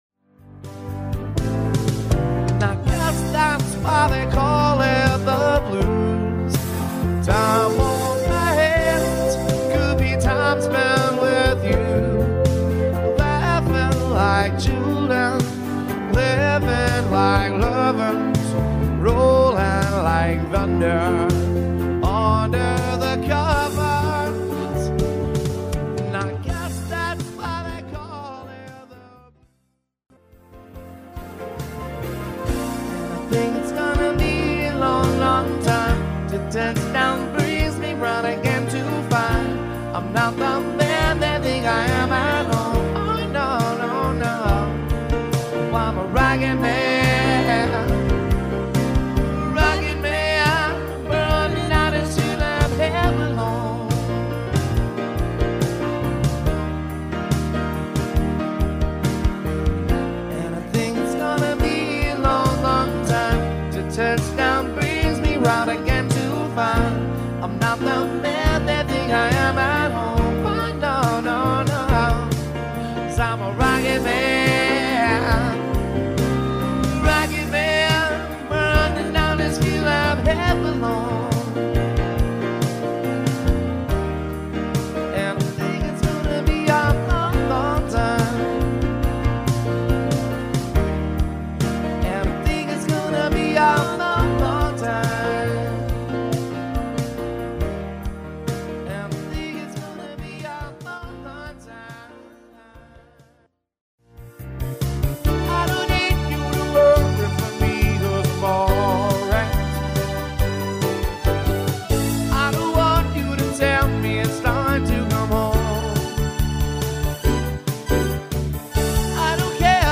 The mix may not always be perfect, you may here some
minor distortion, you will here some background noise.